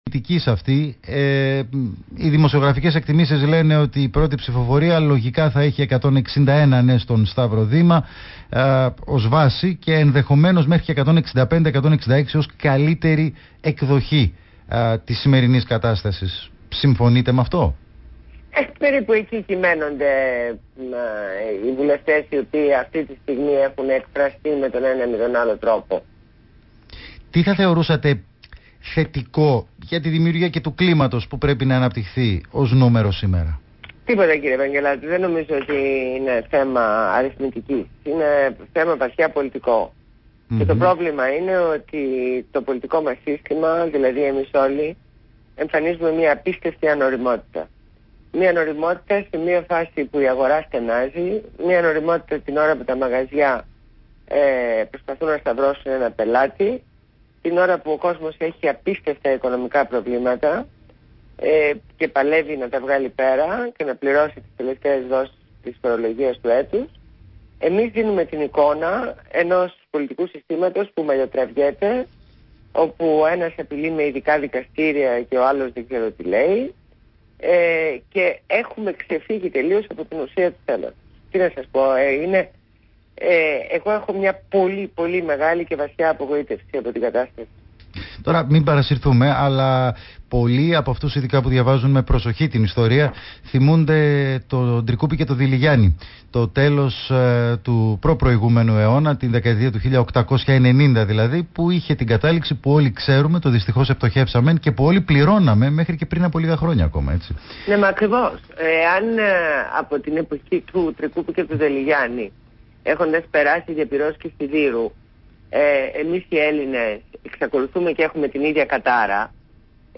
Συνέντευξη στο ραδιόφωνο Παραπολιτικά